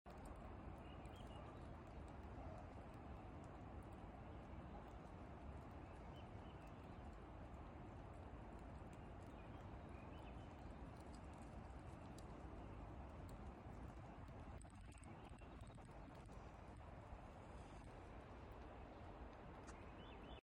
Relaxing Nature sounds AFT 2024 sound effects free download